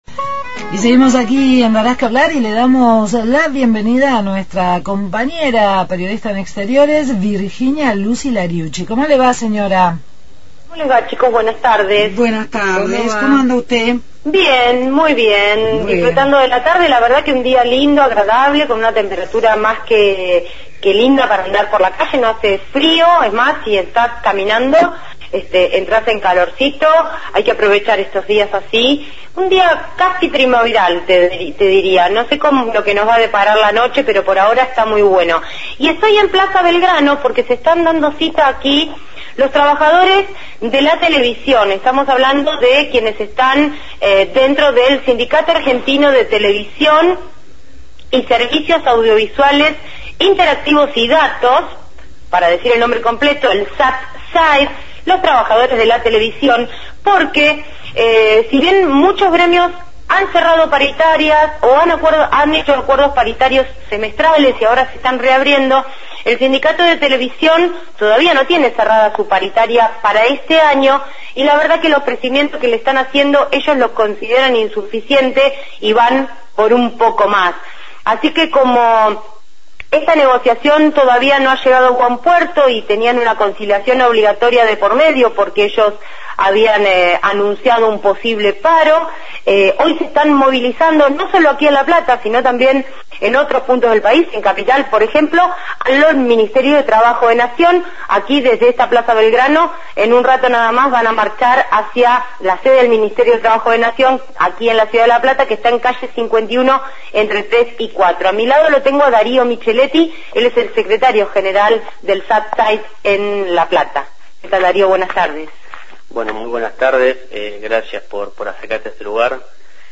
Móvil/ Reclamo salarial de trabajadores de Telecomunicaciones – Radio Universidad